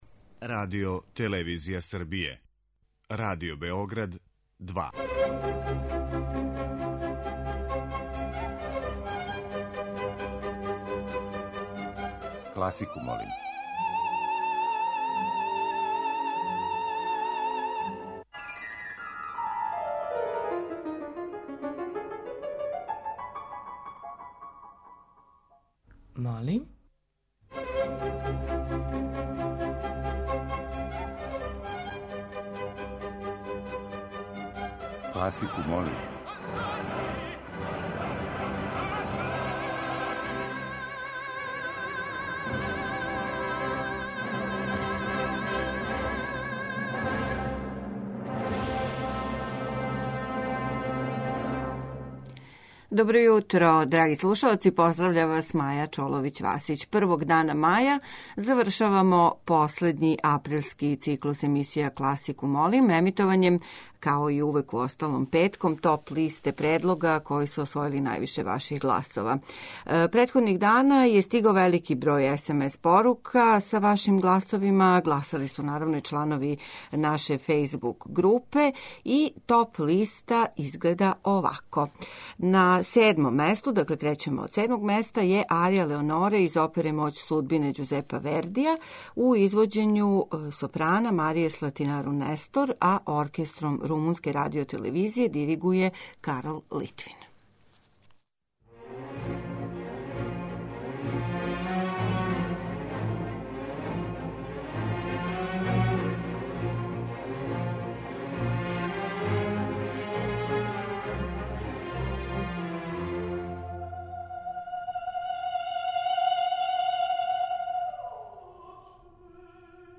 Избор за недељну топ-листу класичне музике Радио Београда 2